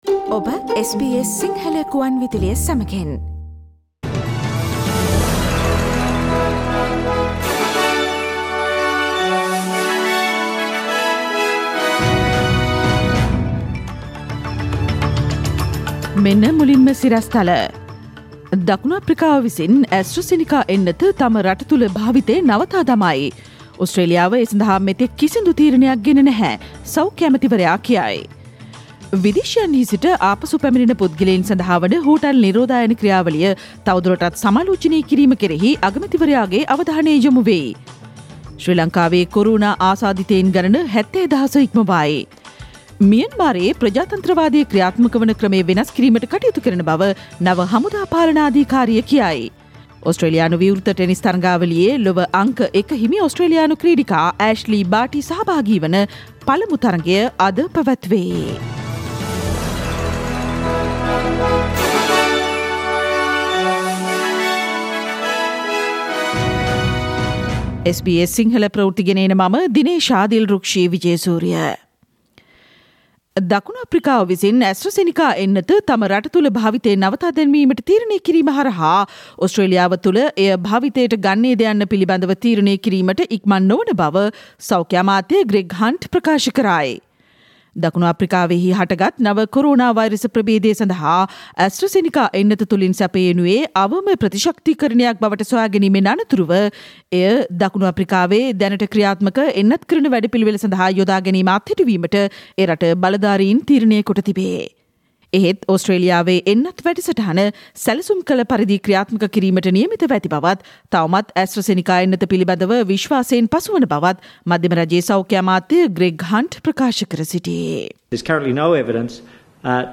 SBS Sinhala radio news on 9 February 2021.